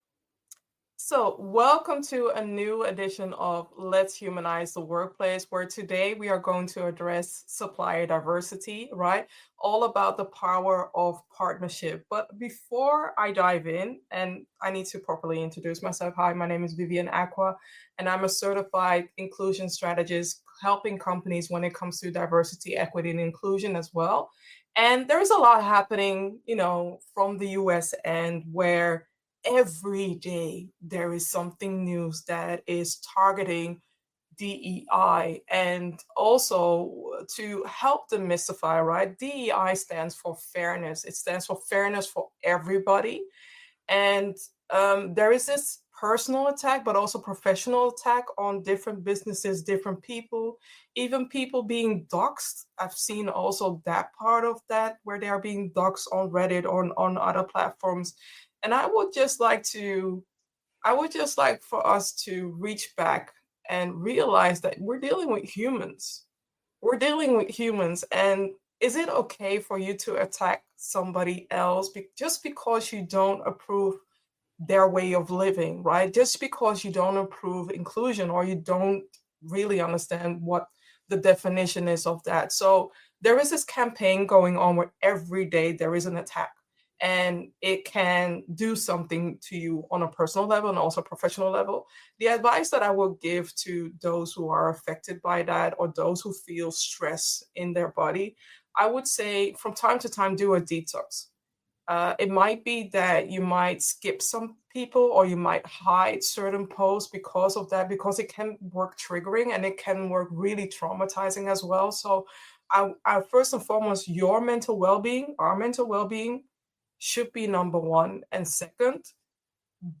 Whether you’re new to the concept or looking to refine your approach, this conversation promises to deliver fresh perspectives, actionable insights, and inspiring stories on building stronger partnerships for a better future.